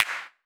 edm-clap-33.wav